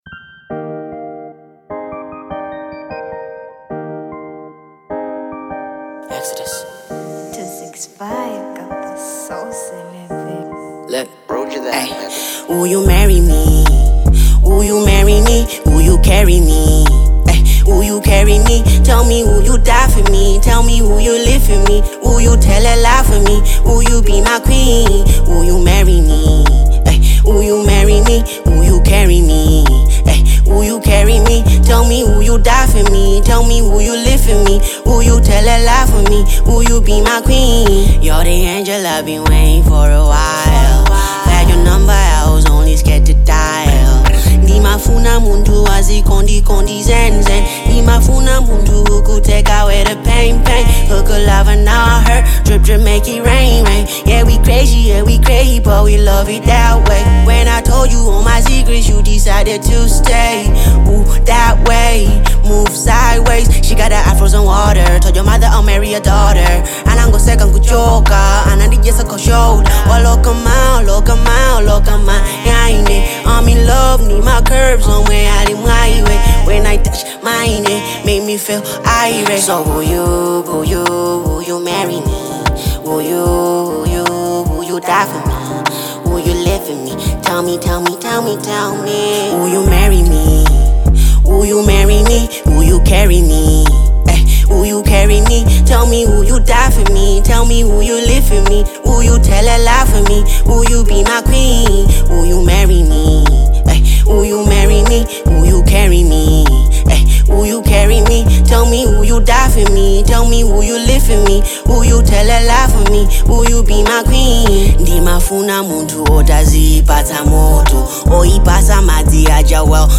Genre : Afro-Pop